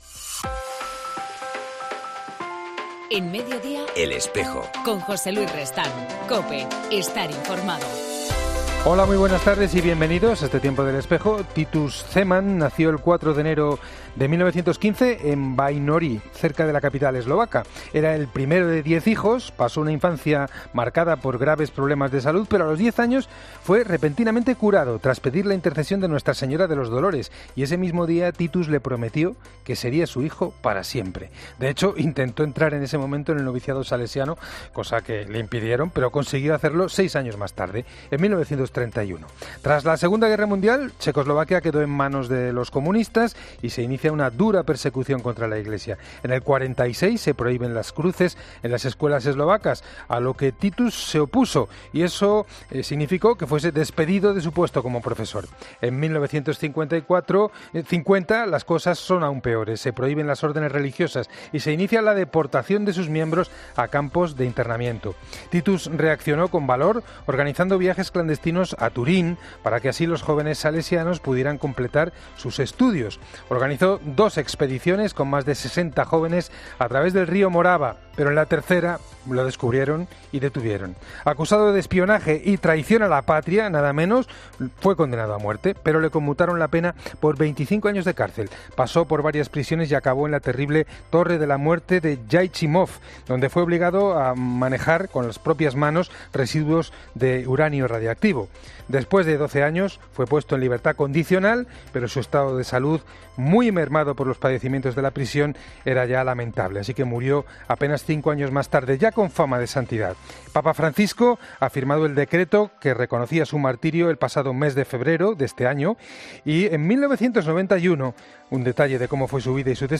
En El Espejo del 2 de octubre hemos hablado con Jesús Ruiz, obispo auxiliar de la Diócesis de Bangassou, en la República Centroafricana